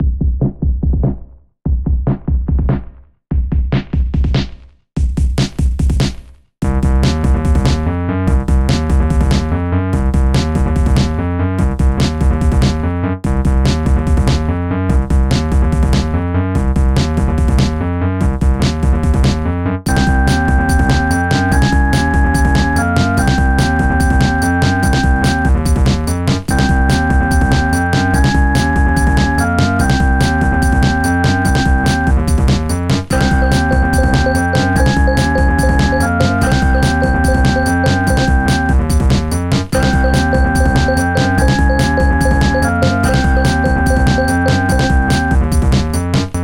Bucle de Big Beat
Música electrónica
repetitivo
sintetizador